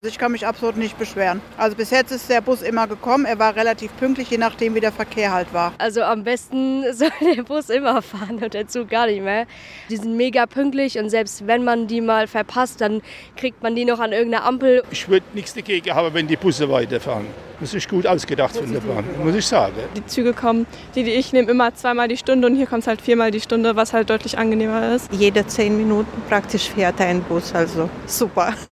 Der SWR hat am Dienstag einige Pendlerinnen und Pendler befragt: